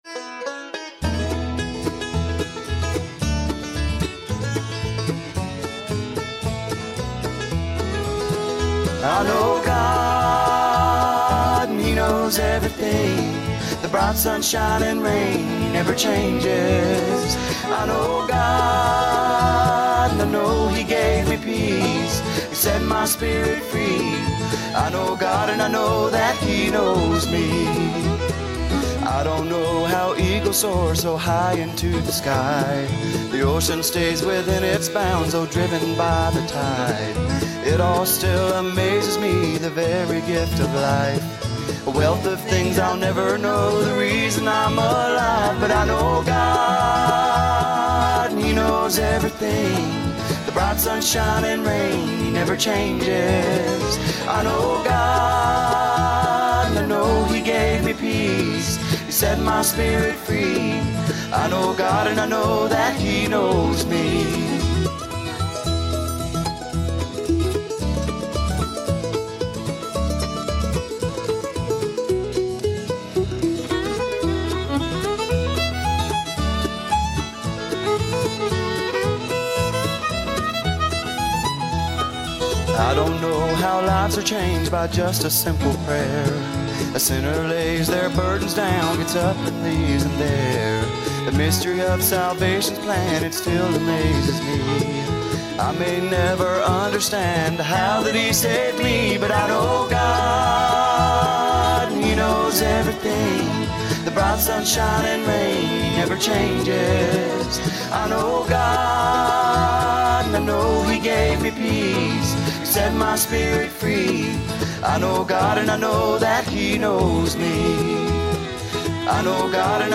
We love bluegrass